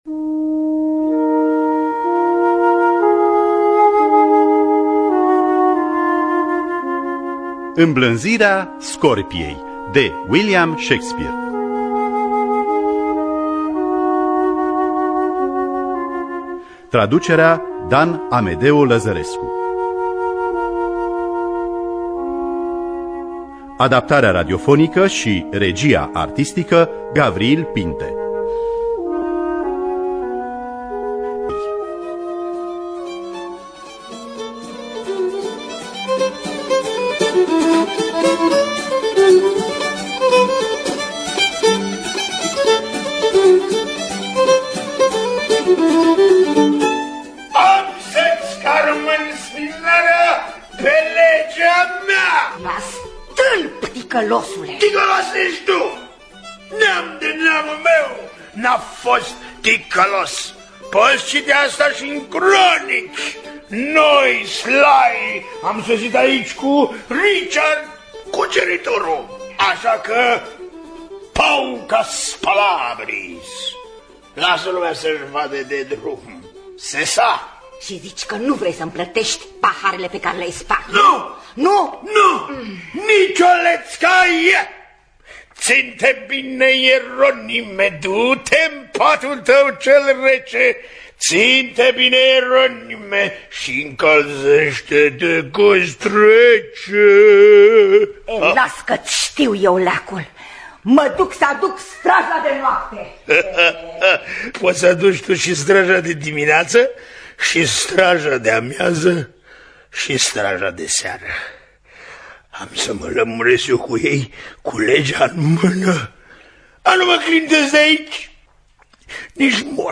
“Îmblânzirea scorpiei” de William Shakespeare – Teatru Radiofonic Online